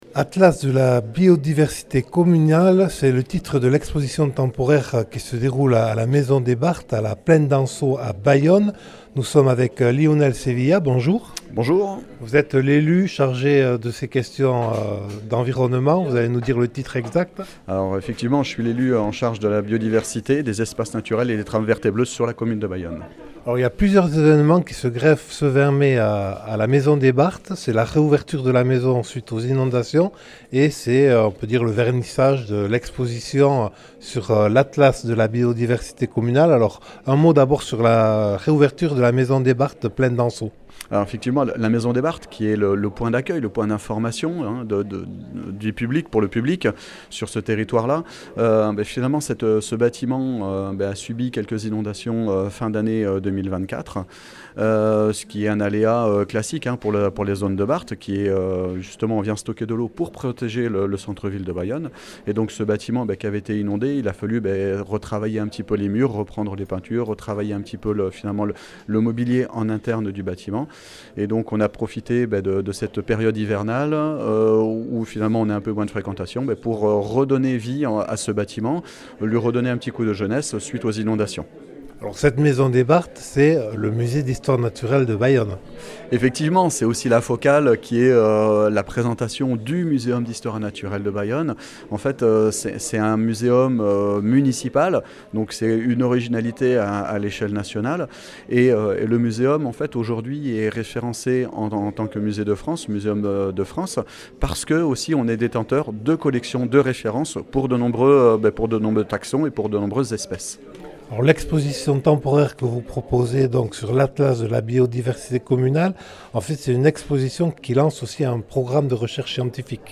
À partir du 20 mai et pendant un an et demi, le Muséum d’histoire naturelle de Bayonne propose une exposition consacrée à la biodiversité locale : rencontre avec Lionel Sevilla, conseiller municipal délégué au patrimoine naturel et à la biodiversité et Jean-René Etchegaray, maire de Bayonne.
Interviews et reportages